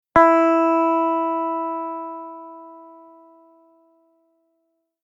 Simply click the play button to get the sound of the note for each string (E, A, D, G, B and E).
High E String
high-e-note.mp3